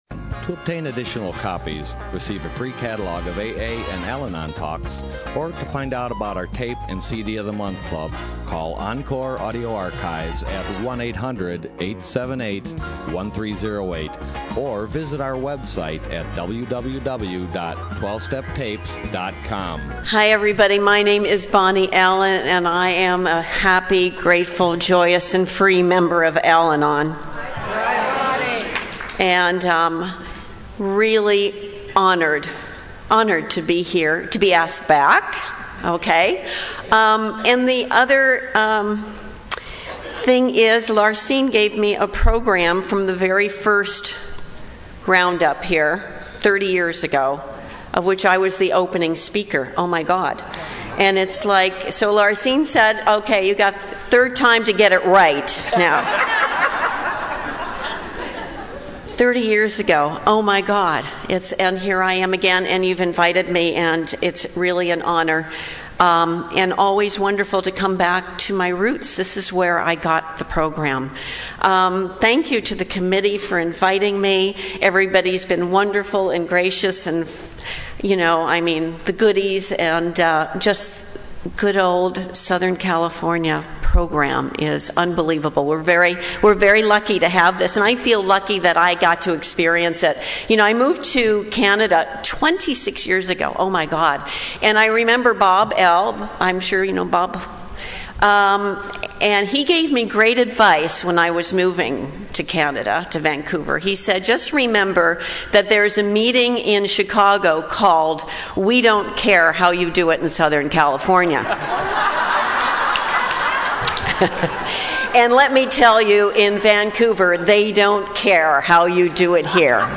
SOUTHBAY ROUNDUP 2014